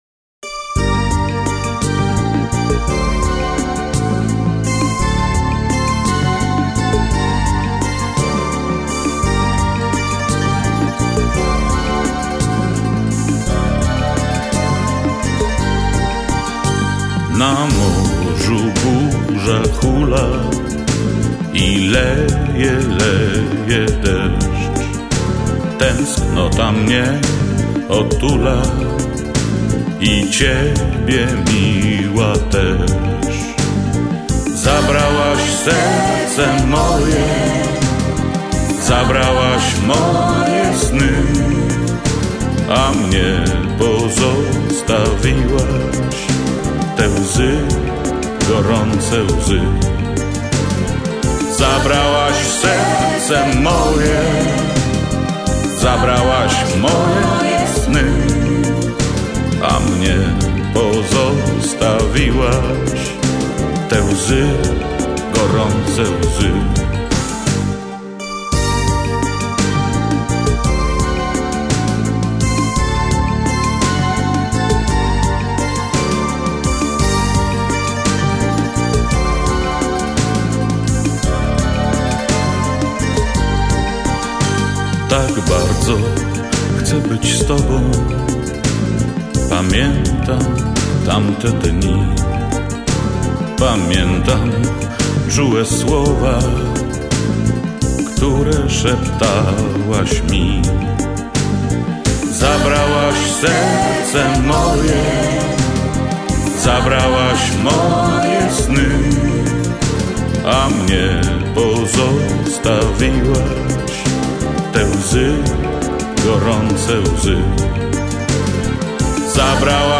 Я ЇЇ виконую в стилі Віденьск вальс На "па 50"